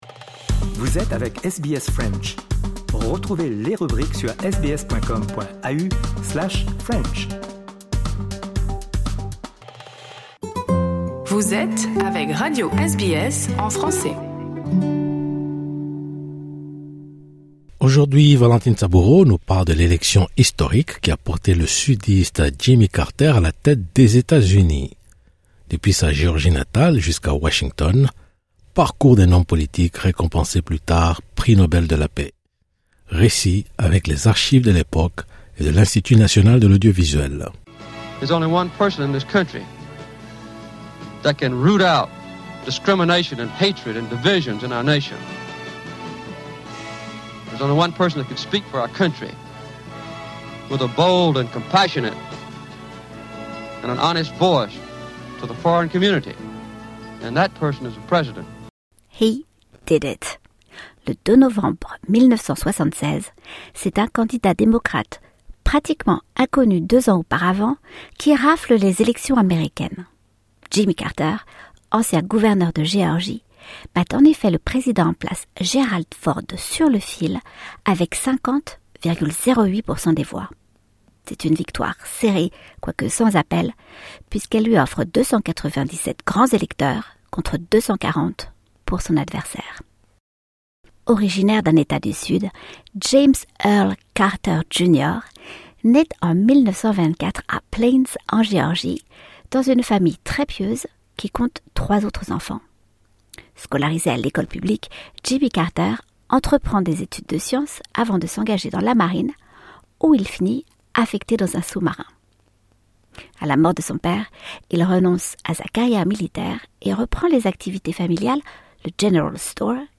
Depuis sa Géorgie natale jusqu’à Washington, parcours d’un homme politique récompensé plus tard prix Nobel de la paix. Récit, avec les archives de l’époque et de l’Institut national de l’audiovisuel.